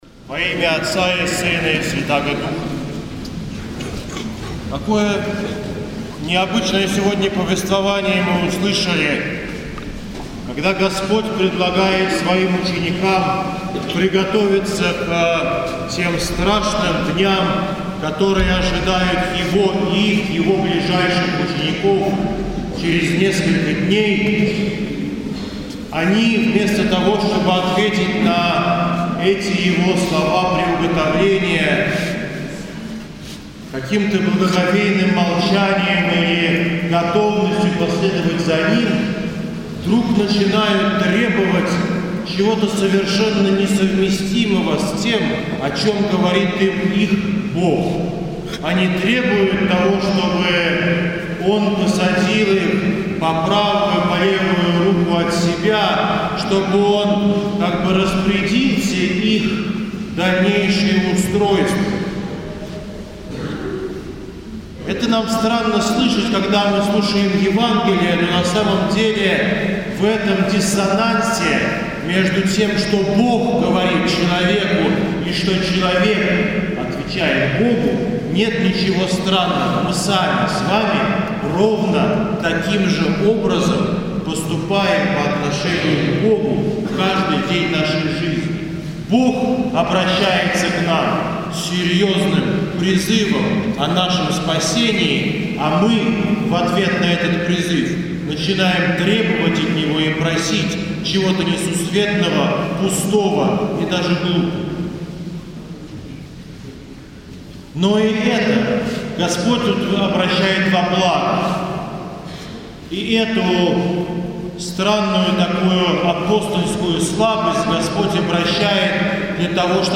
Проповедь в 5- неделю Великого поста, преподобной Марии Египетской.